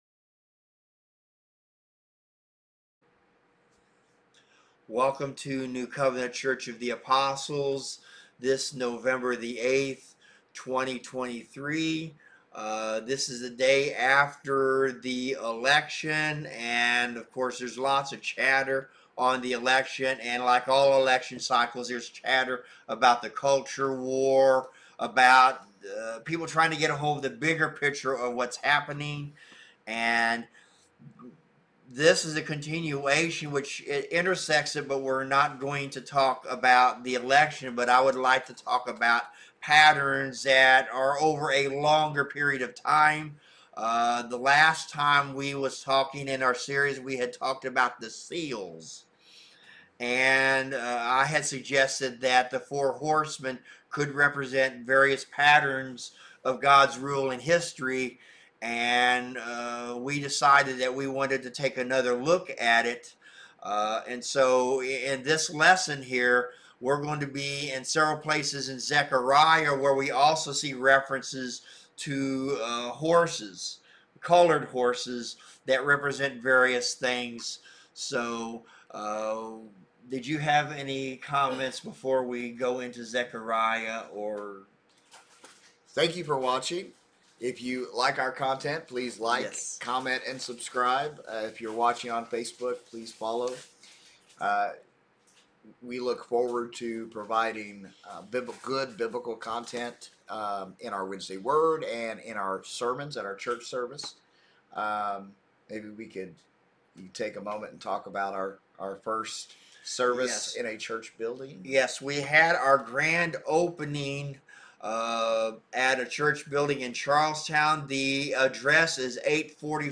Wednesday Word Bible Study